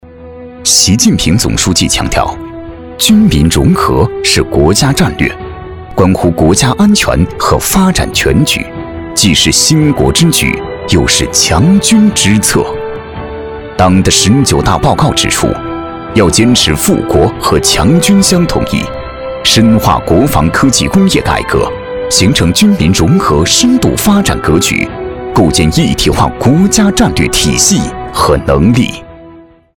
大气震撼 企业专题,人物专题,医疗专题,学校专题,产品解说,警示教育,规划总结配音
品质男音，节奏感强富有感情。大气磁性，浑厚稳重。